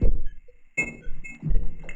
Abstract Rhythm 20.wav